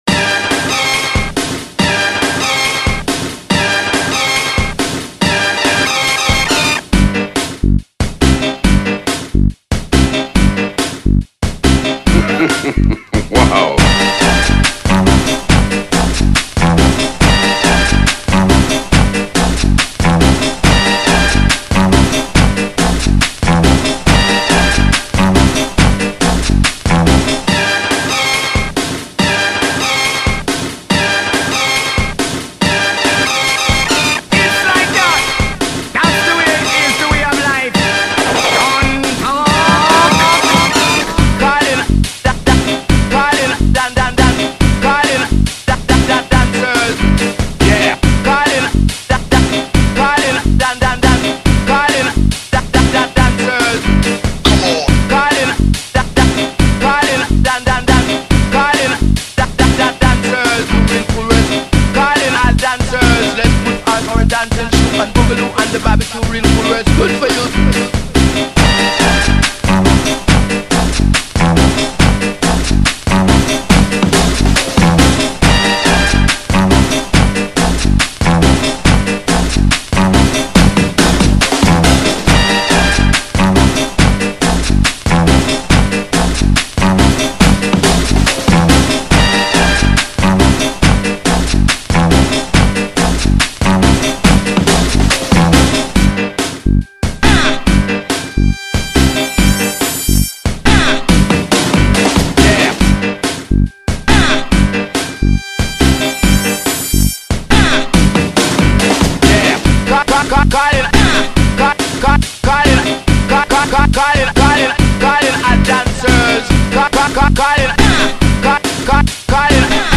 Прикольный break beat